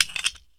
inv_detach_addon.ogg